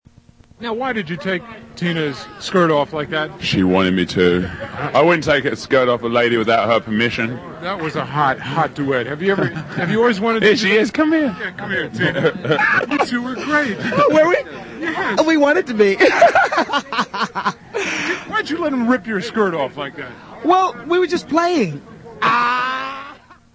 I happened to be at the perfect spot when Tina exited stage right.
Listen as I, er. . .debrief Mick and Tina about the de-skirting:
mick-tina-live-aid-1.mp3